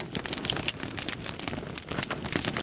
furnace_burning.ogg